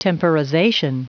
Prononciation du mot temporization en anglais (fichier audio)
temporization.wav